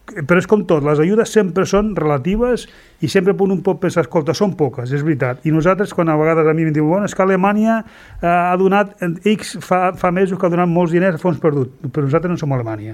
Juli Fernández, portaveu del PSC a Pallafrugell, ha explicat en una entrevista al Supermatí de Ràdio Capital que el seu partit no ha donat suport a l’últim paquet d’ajudes municipals de 10 MEUR perquè majoritàriment no és d’ajudes pel COVID-19.